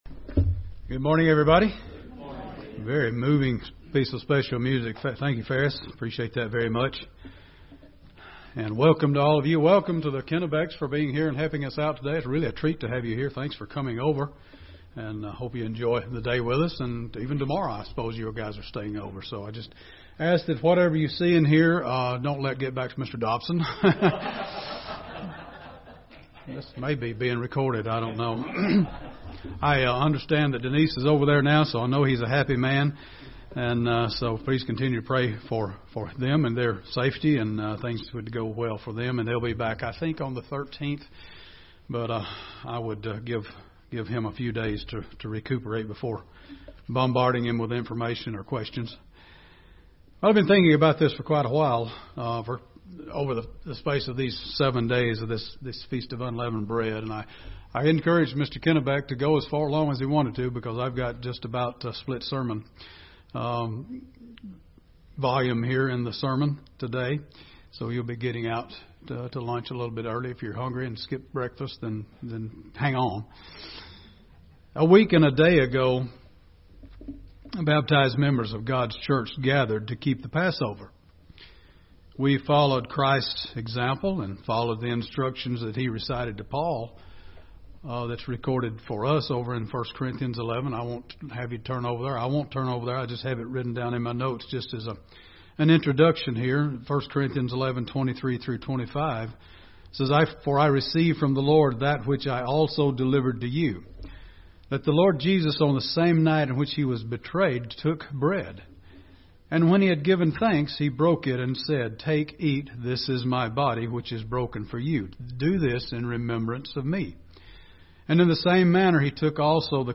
Keeping the days of unleavened bread and all of God's Holy Days help us affirm our commitment to stay the course in spite of trial or hardship. This sermon challenges us to examine our personal level of commitment to God's way of life in good times or bad.